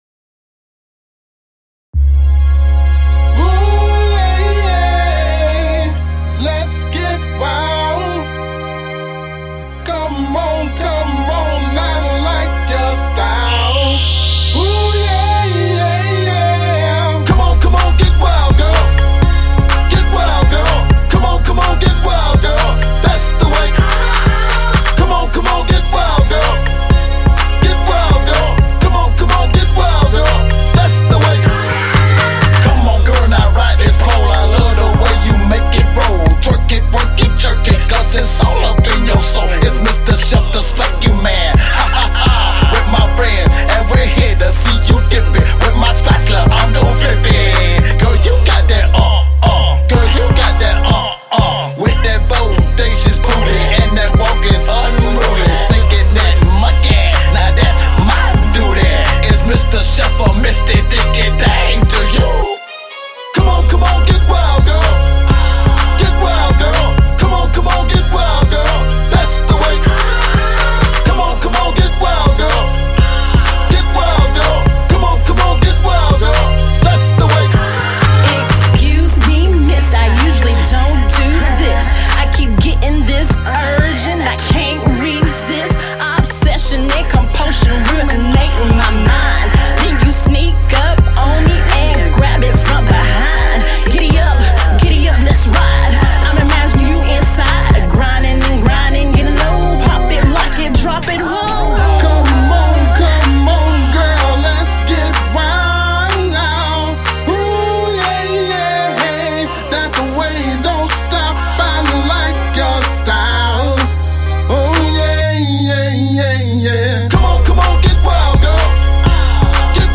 R&B singing
R&B, POP, Hip Hop & Rap.